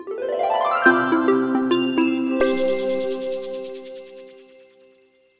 PowerOn.wav